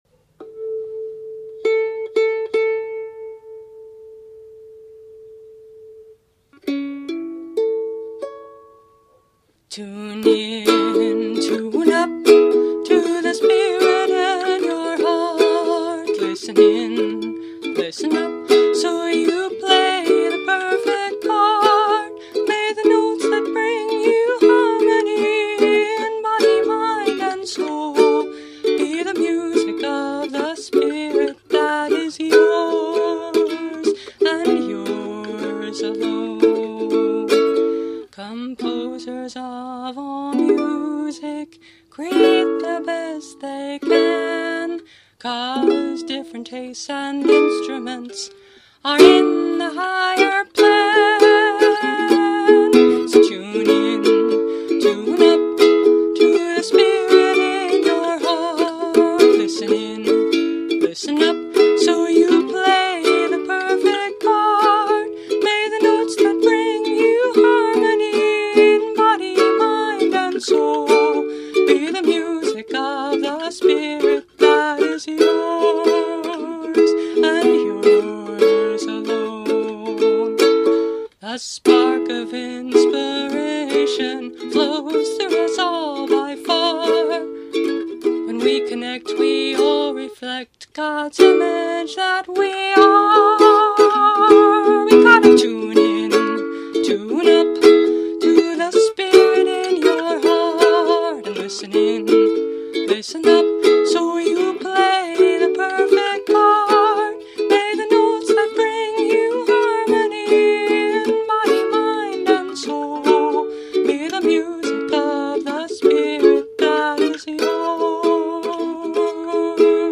I realize this song isn’t very meditative.
And with its upbeat tempo, it can help us snap out of negativity.
Instrument: Concert Flea ukulele